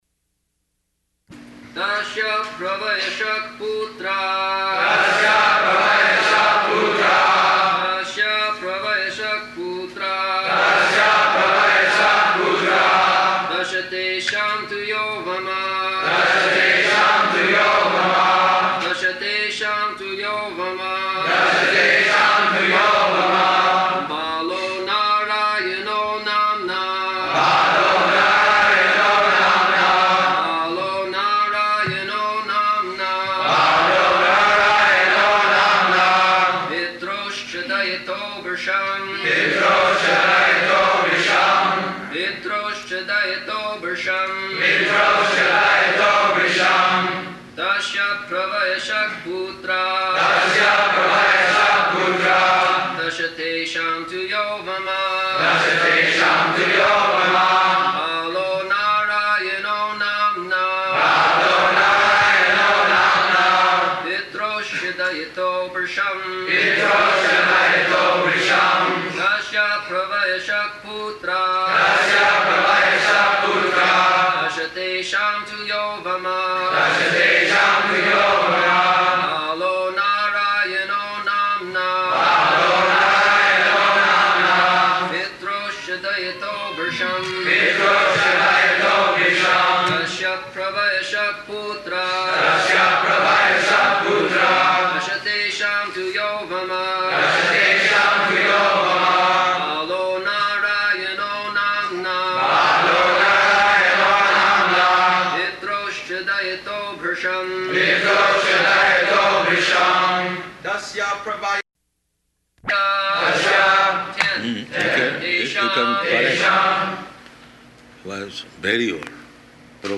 Location: Chicago
[leads chanting of verse, etc.]